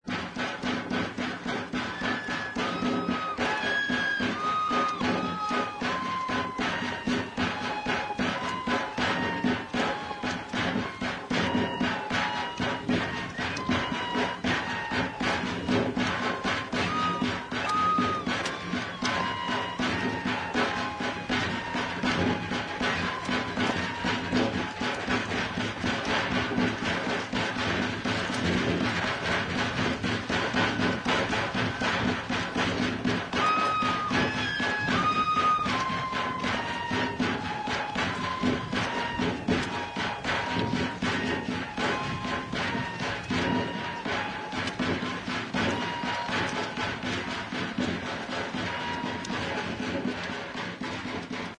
Aerófonos -> Flautas -> Recta (de una mano) + flautillas
DANZA DE PINTOS. Procedencia: Norogachi y Guachochi.
FLAUTA; FLAUTA RARAMURI